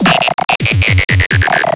Index of /90_sSampleCDs/USB Soundscan vol.26 - Big Beat Samples & More [AKAI] 1CD/Partition F/ESO SEQS KIT